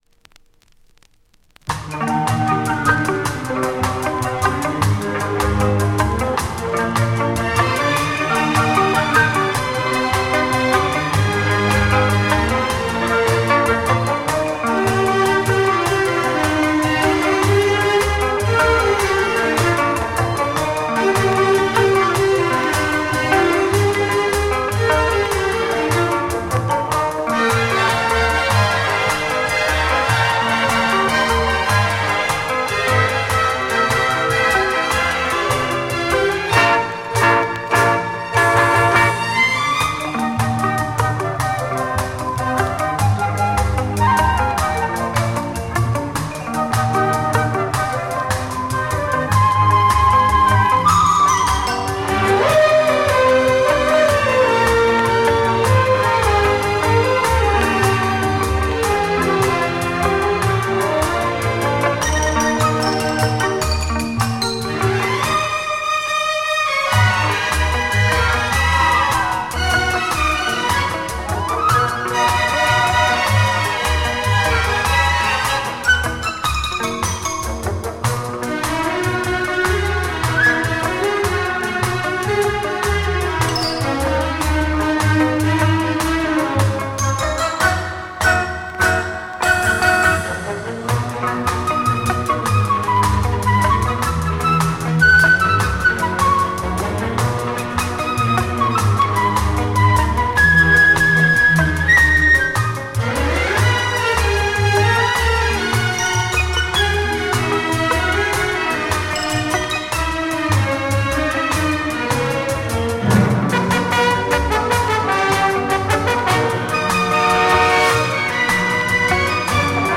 Rare French 60s Sountrack EP
Very rare early 60s French movie soundtrack